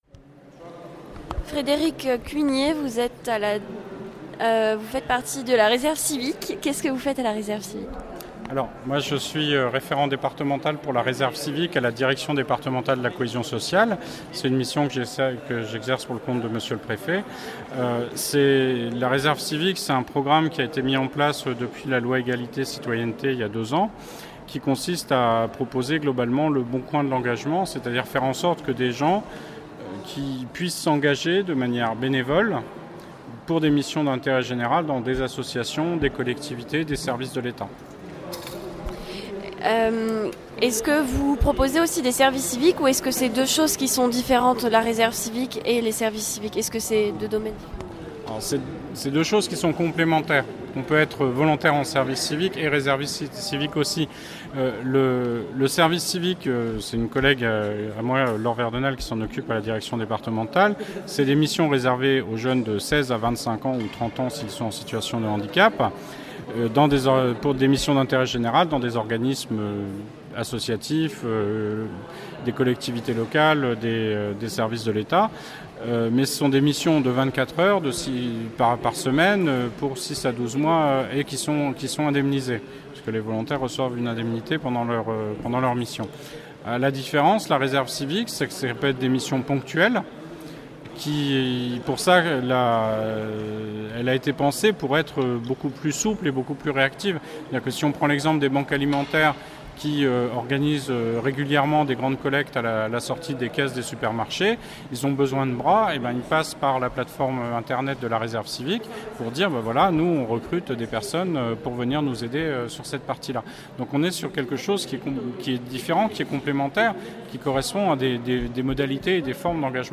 Les officiels de la vie étudiantes (transports, logements, restauration, sécu, job, etc.) et quelques associations nancéiennes se sont réunies pour un salon à l’Hôtel de ville de Nancy le samedi 7 juillet 2018.